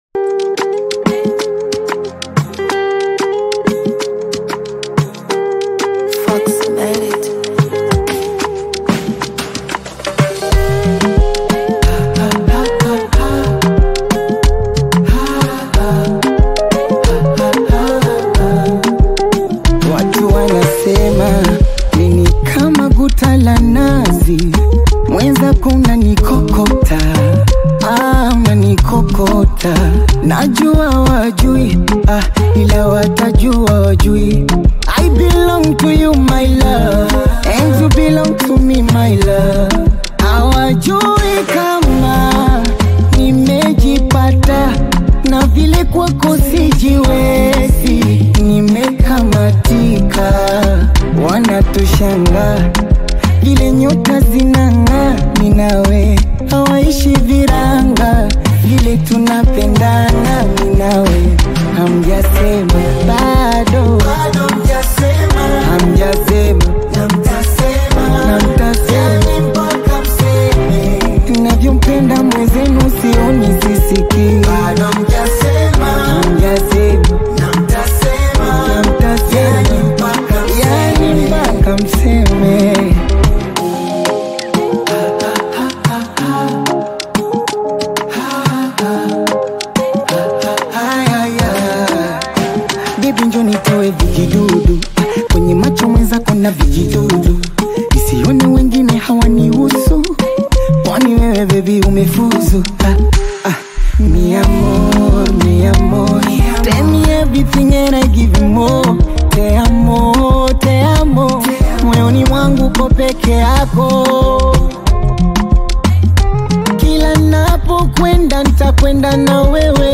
infectious beats, creating a lively and energetic track.